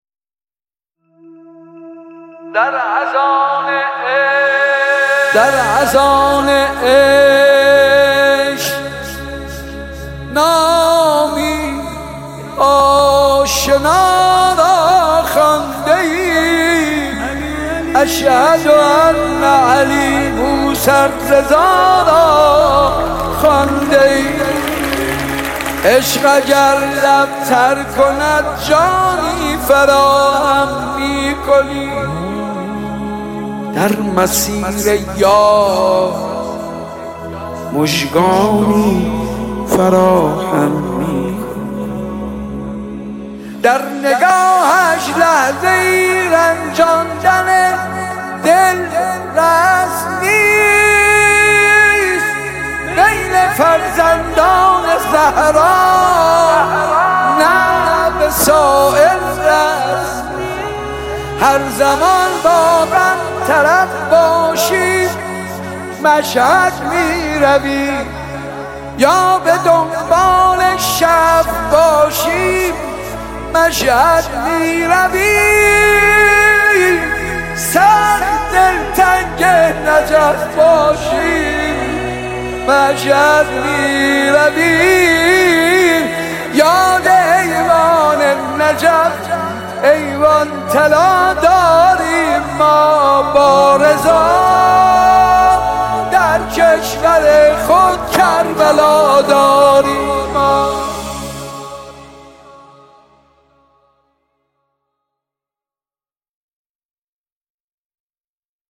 نماهنگ زیبای
با صدای دلنشین